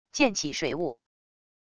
溅起水雾wav音频